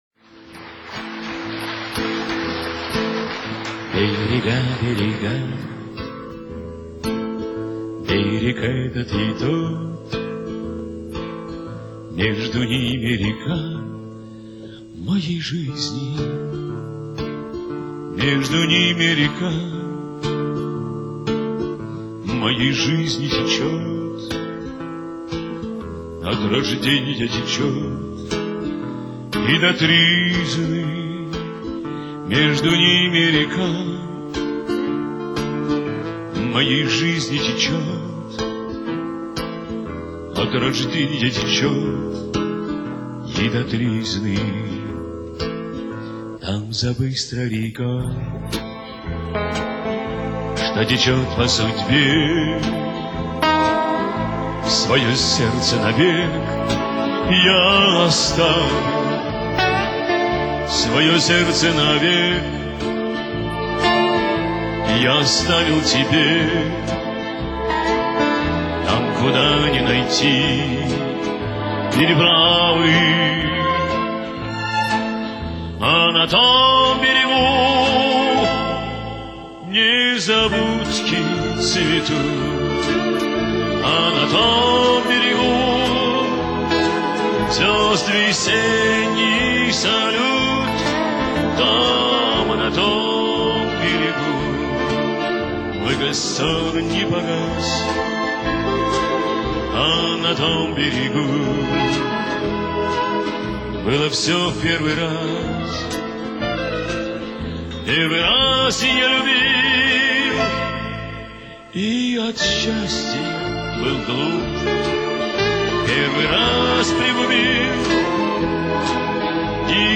Сейчас загружу.. А пока вот этот женский вариант послушайте